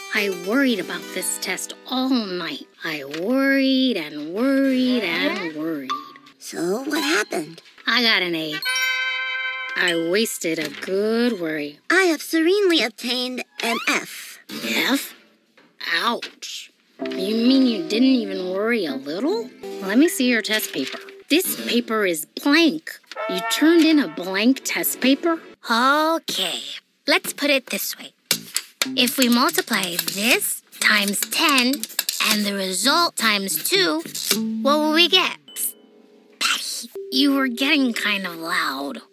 Animation
Voice Type:  Expressive, rich, conversational, with a touch of rasp.